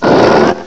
sovereignx/sound/direct_sound_samples/cries/ursaluna.aif at 5119ee2d39083b2bf767d521ae257cb84fd43d0e